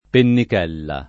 vai all'elenco alfabetico delle voci ingrandisci il carattere 100% rimpicciolisci il carattere stampa invia tramite posta elettronica codividi su Facebook pennichella [ pennik $ lla ] s. f. — voce roman. per «pisolino»